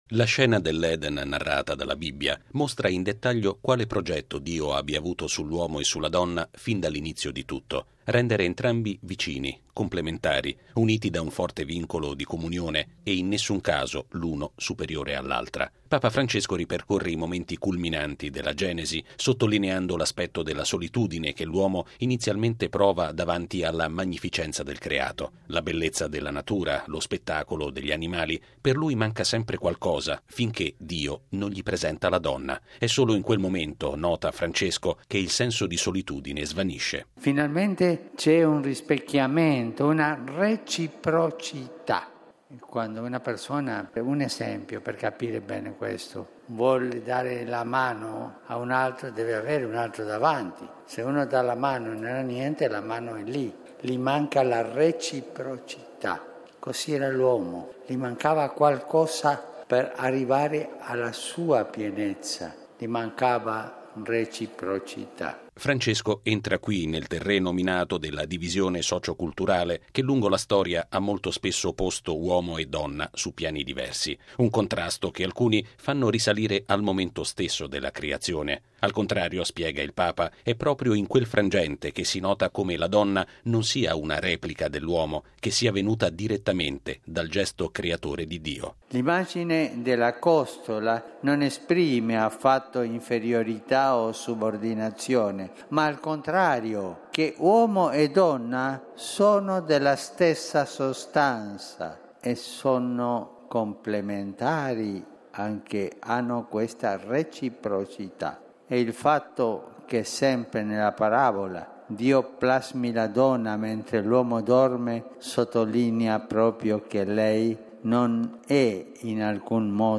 È l’auspicio che ha attraversato la catechesi di Papa Francesco all’udienza generale in Piazza San Pietro, davanti a oltre 40 mila persone, dedicata ancora una volta al rapporto di “reciprocità” che lega l’uomo e la donna.